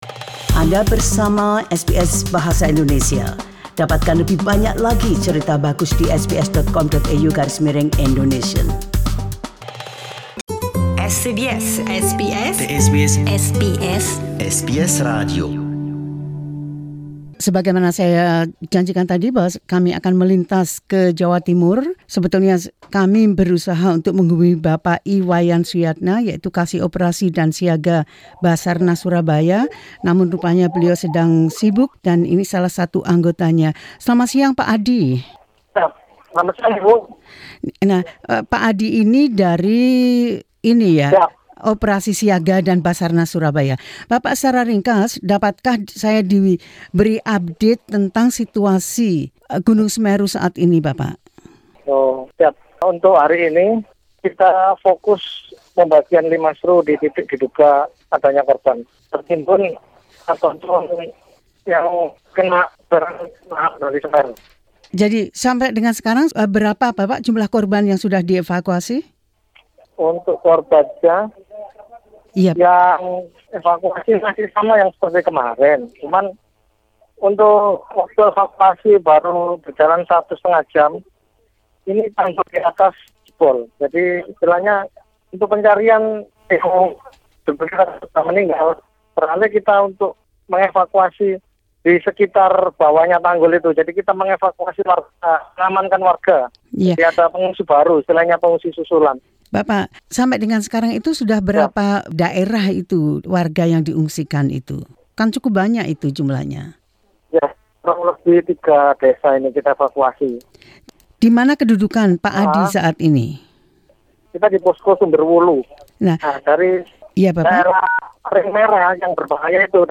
Laporan dari lapangan saat Gunung Semeru meletus